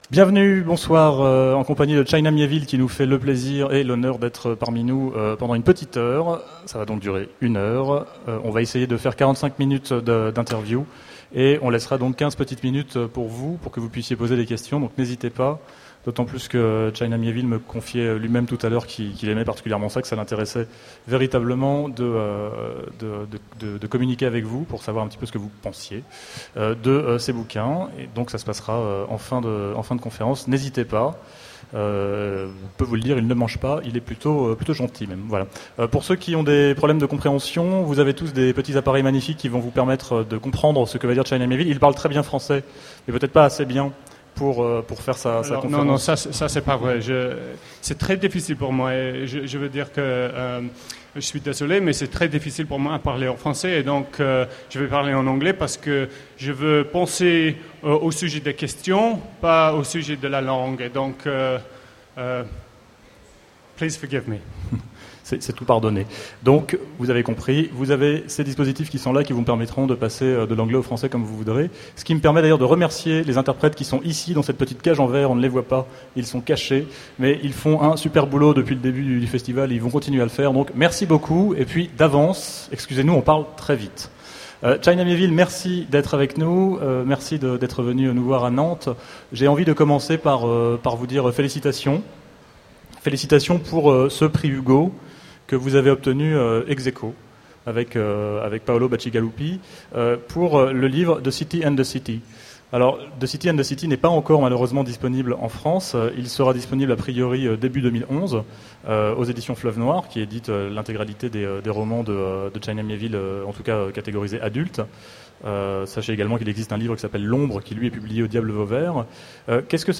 Voici l'enregistrement de la rencontre avec China Miéville aux Utopiales 2010.